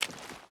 Water Walk 4.ogg